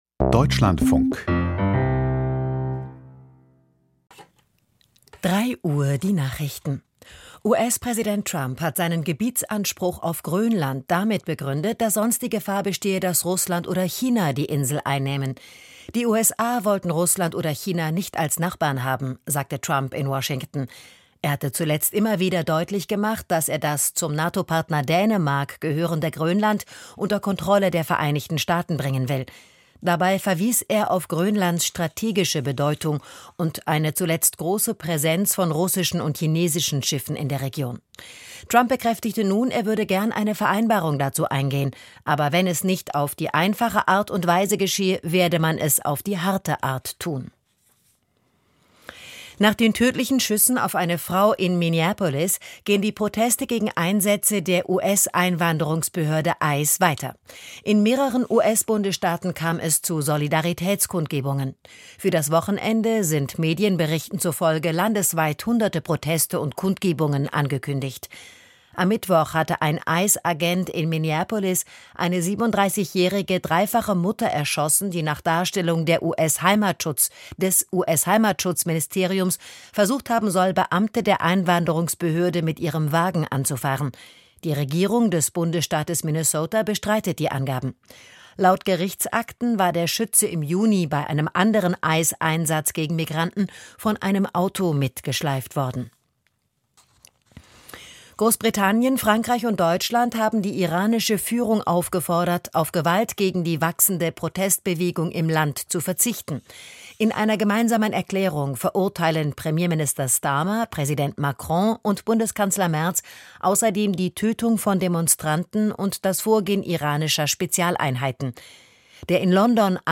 Die Nachrichten vom 10.01.2026, 03:00 Uhr
Aus der Deutschlandfunk-Nachrichtenredaktion.